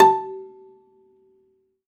53p-pno13-A2.wav